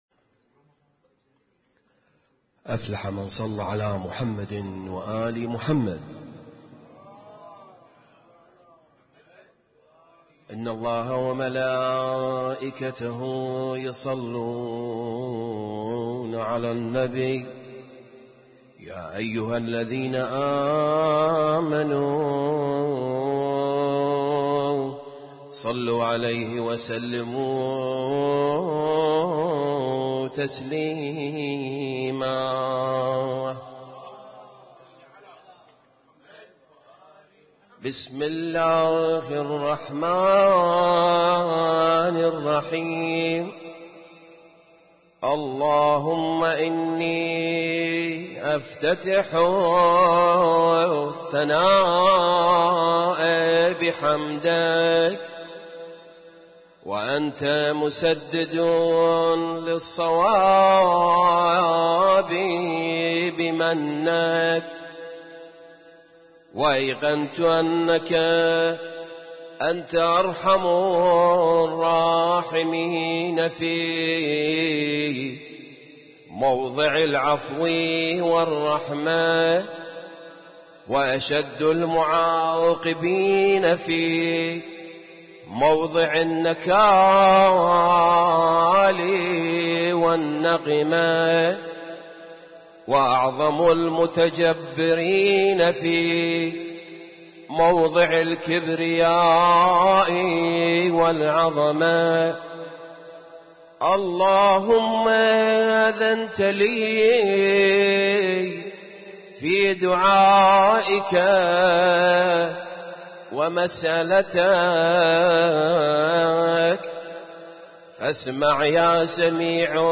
Husainyt Alnoor Rumaithiya Kuwait
اسم التصنيف: المـكتبة الصــوتيه >> الادعية >> دعاء الافتتاح